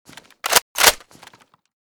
ash12_unjam.ogg.bak